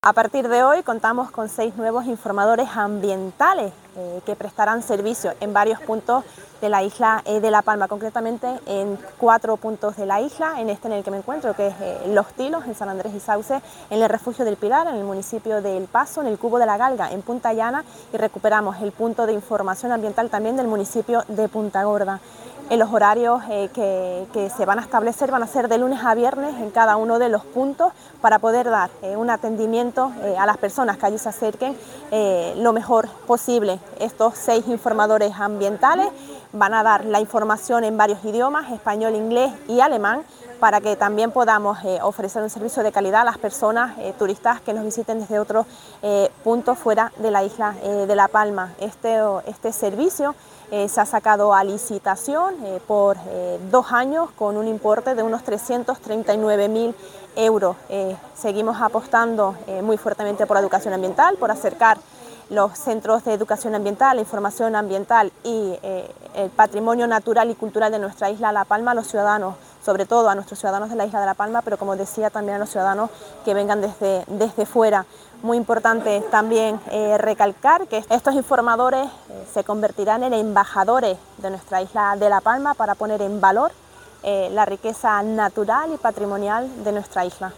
Declaraciones María Rodríguez audio.mp3